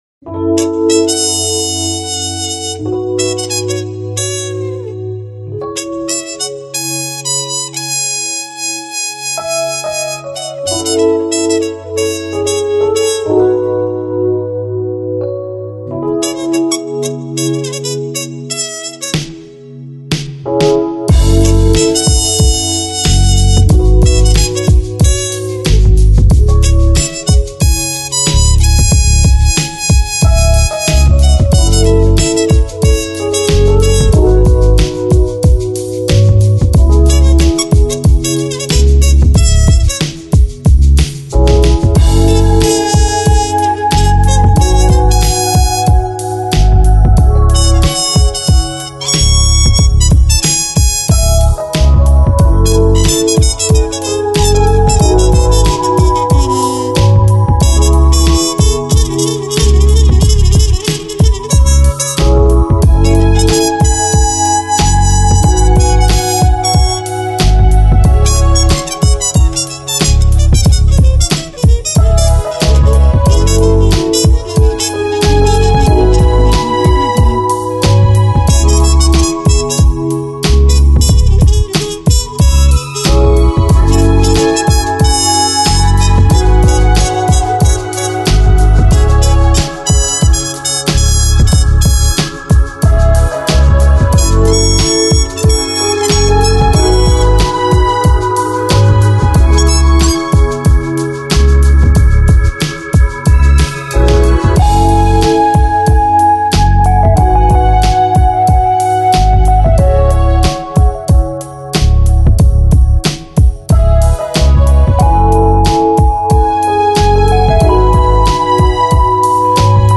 Lounge, Chill Out, Smooth Jazz Продолжительность